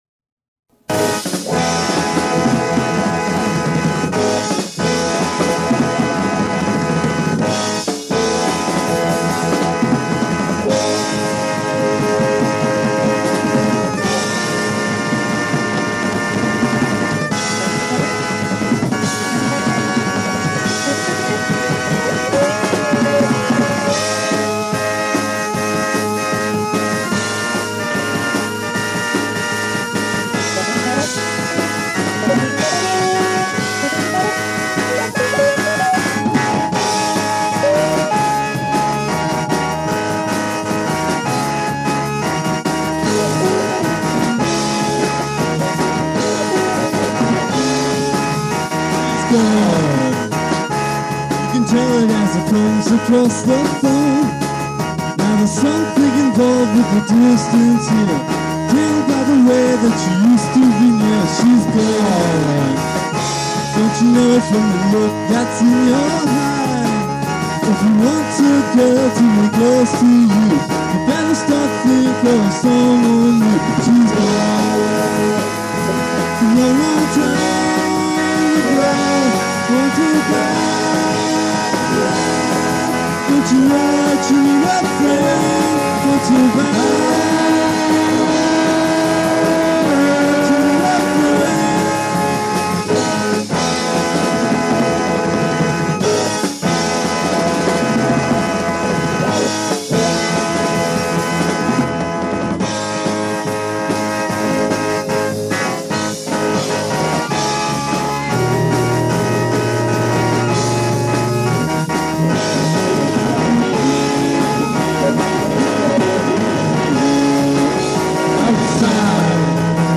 percussion
keyboards
guitar, vocals